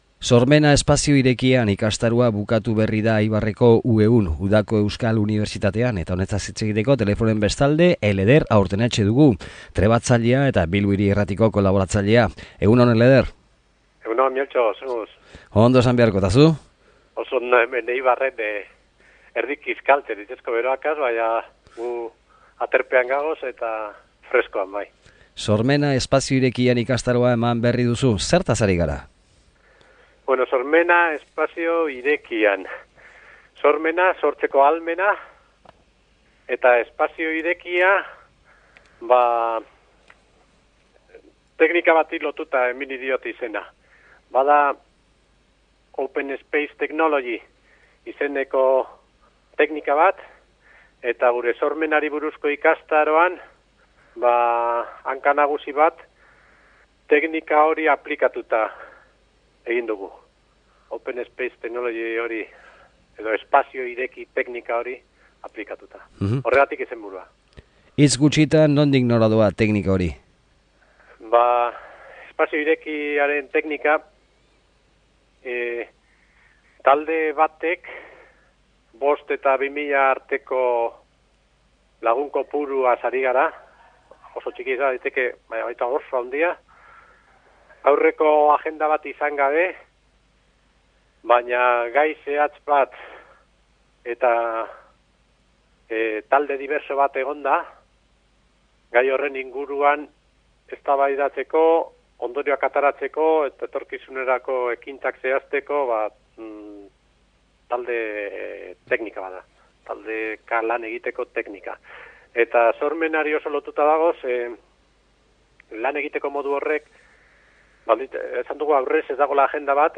Oso interesatua bera eta galdera sakonak , buru-mapak eta sormena gai hartuta. Erreportaje polit hau paratu dute Radiokulturako lagunek.